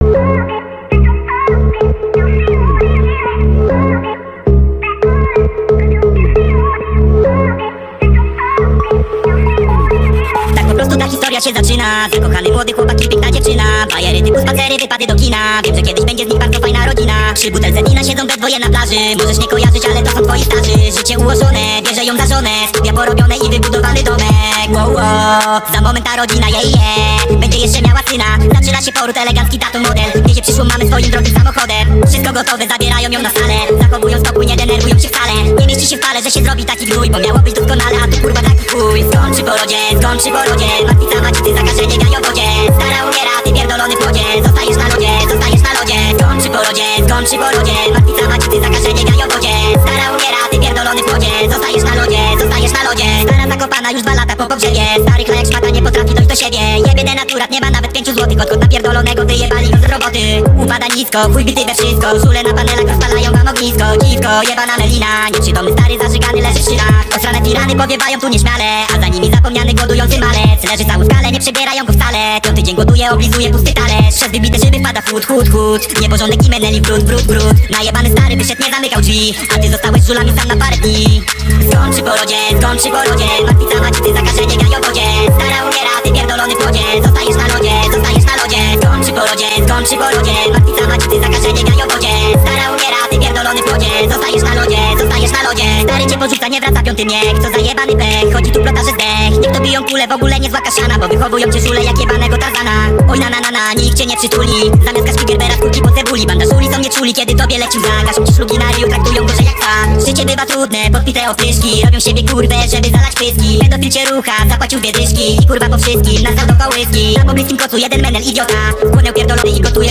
(speed up)